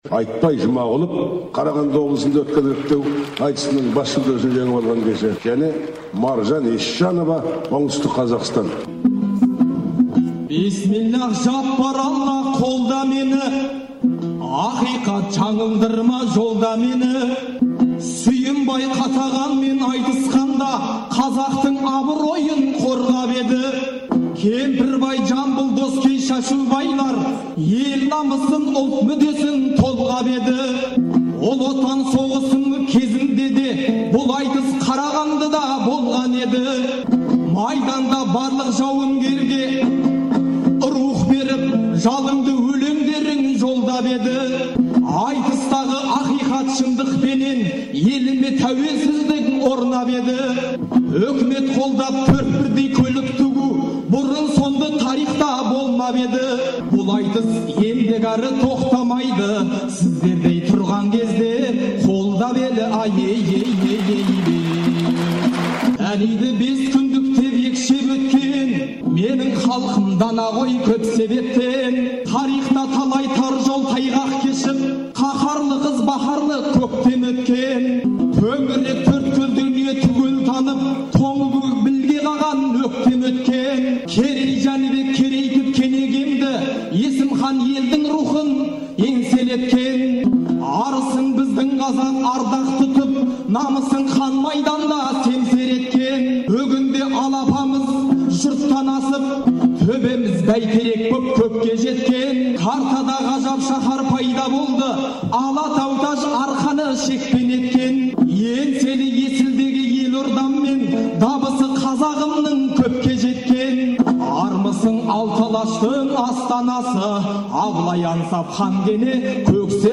Ақындар
айтысы